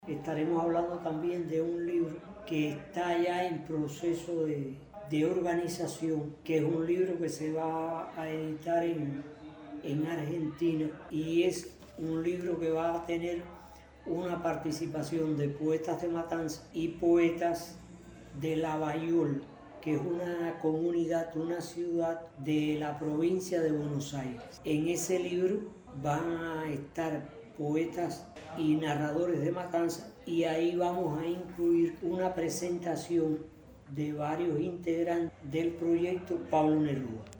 Durante esta semana la casa de altos estudios matancera acogió el Festival Universitario del Libro y la Literatura.